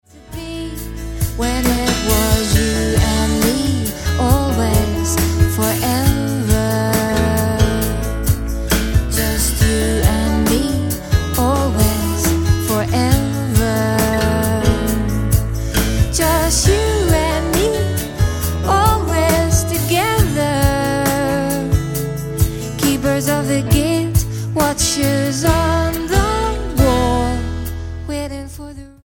STYLE: Roots/Acoustic
spine-tingling, sultry voice